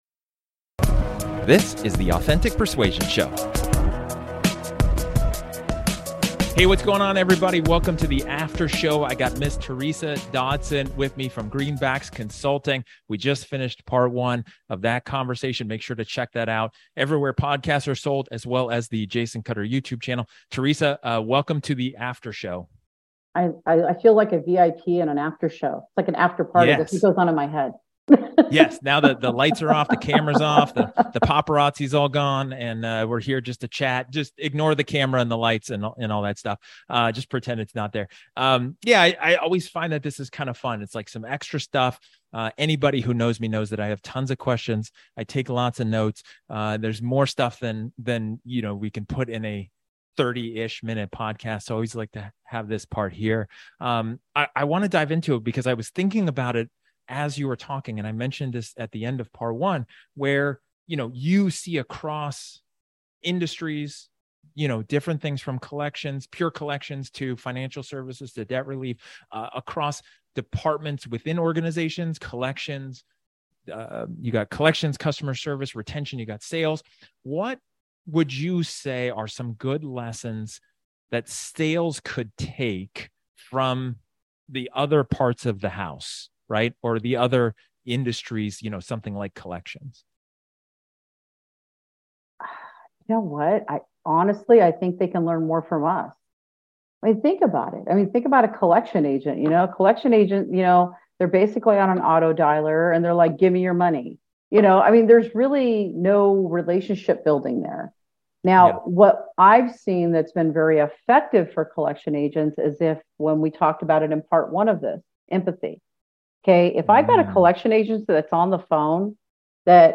This is a casual conversation, off the cuff, and unscripted.